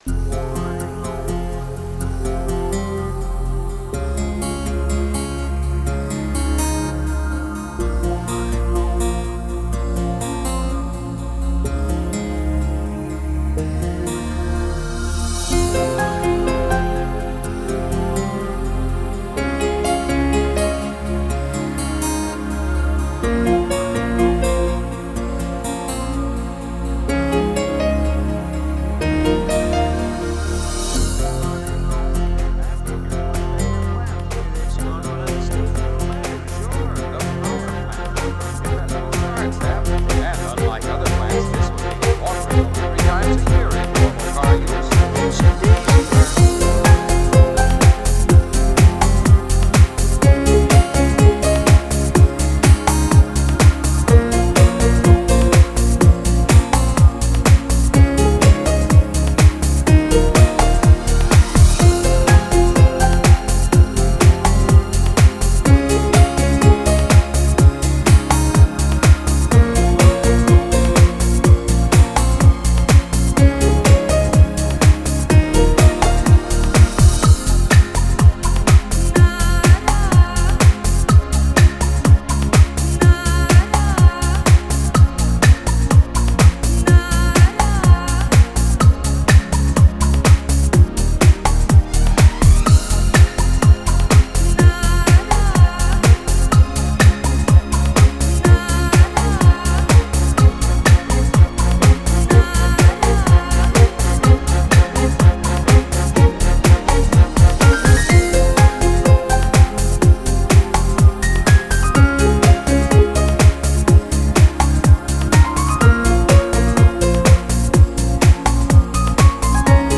强烈的节奏感， 美妙的音乐,奔放的旋律中又伴随着无限的宁静和遐想